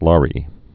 (lärē)